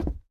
tbd-station-14/Resources/Audio/Effects/Footsteps/floor1.ogg